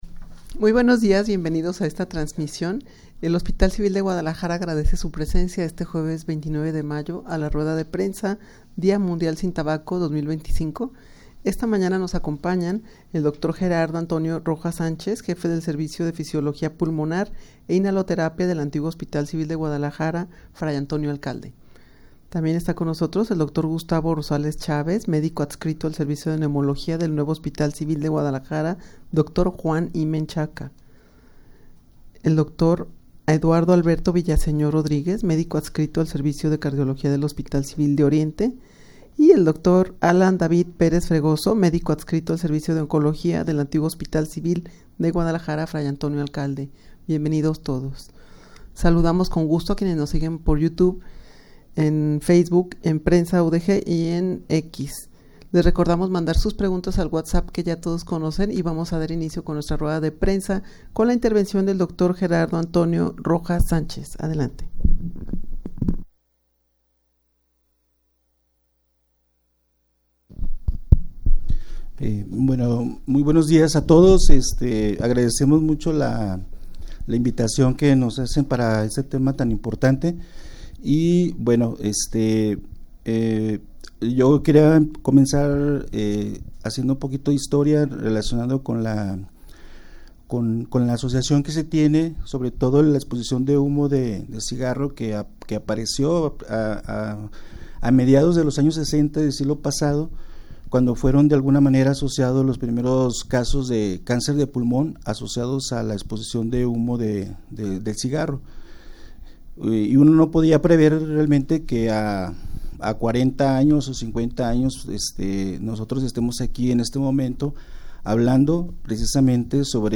Audio de la Rueda de Prensa
rueda-de-prensa-dia-mundial-sin-tabaco-2025.mp3